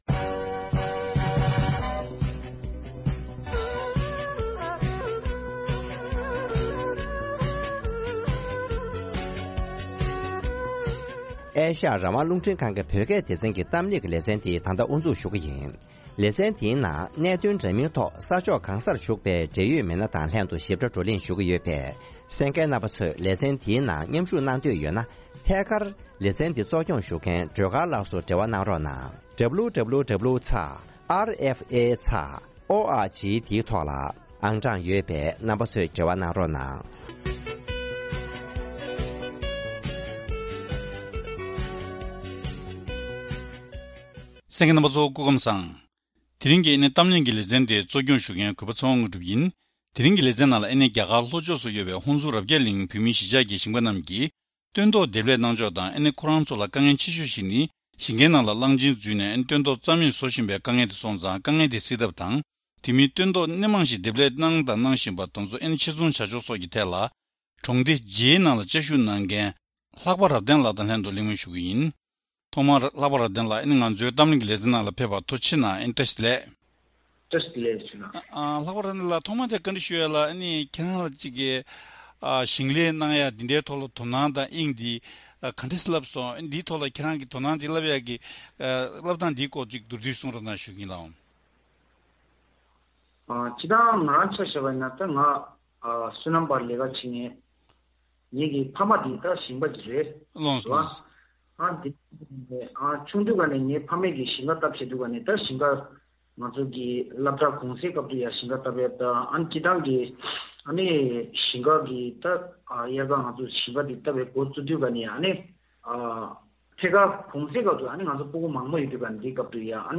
ཐེངས་འདིའི་གཏམ་གླེང་ཞལ་པར་གྱི་ལེ་ཚན་ནང་།